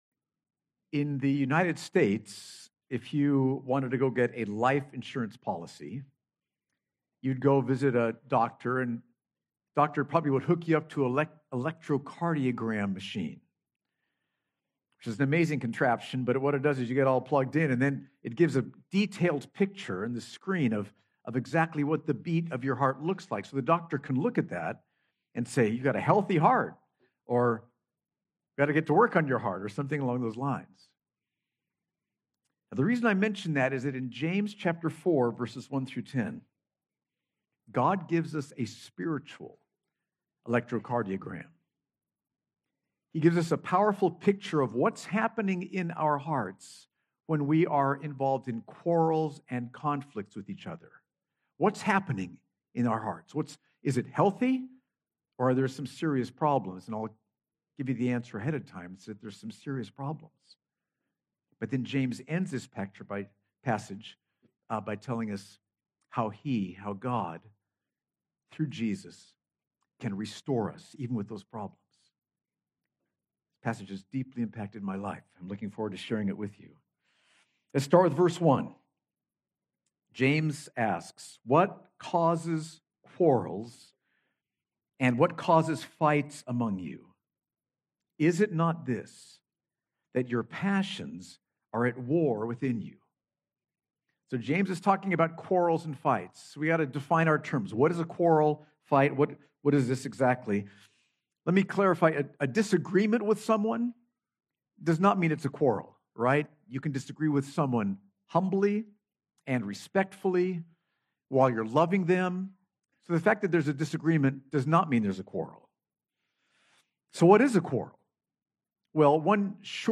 Grace Church Abu Dhabi Sermons